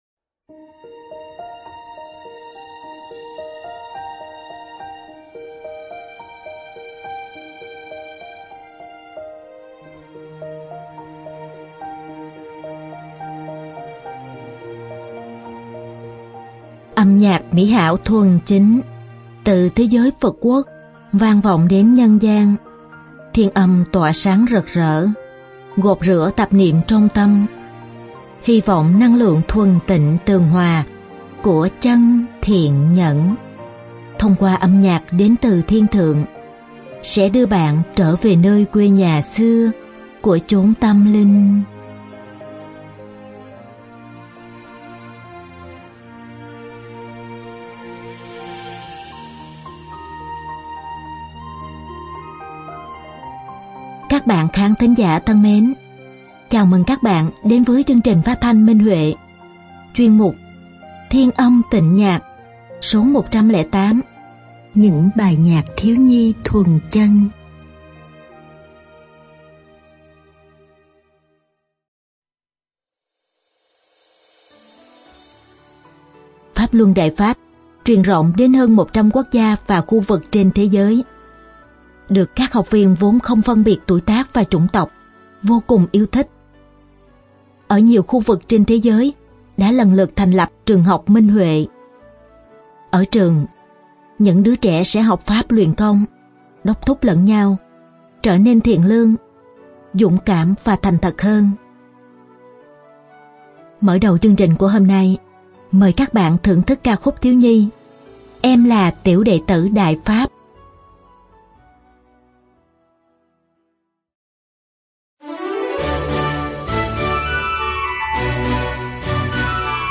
Các bạn khán thính giả thân mến, chào mừng các bạn đến với chương trình phát thanh Minh Huệ, chuyên mục “Thiên Âm Tịnh Nhạc” Số 108: Những bài nhạc thiếu nhi thuần chân.